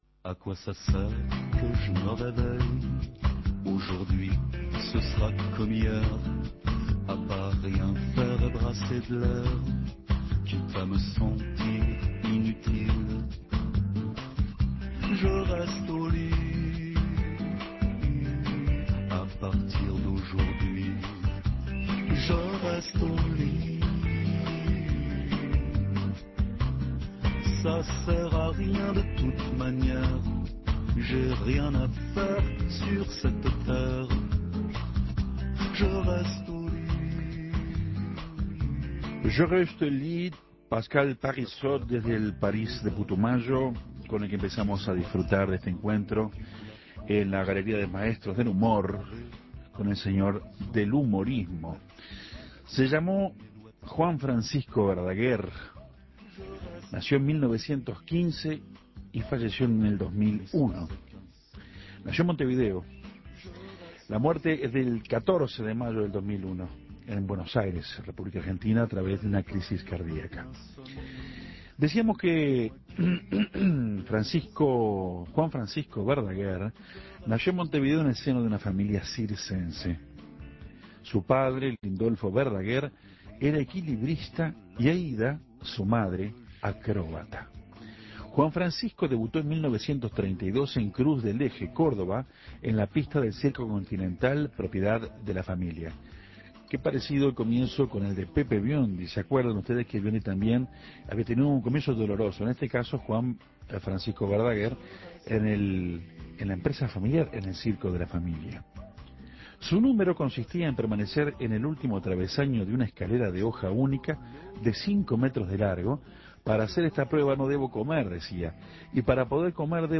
En el ciclo de capocómicos le tocó el turno a uno de los grandes humoristas uruguayos que dejaron su marca: Juan Verdaguer. Compartimos un audio con una de sus presentaciones en vivo y recordamos anécdotas sobre su vida y carrera.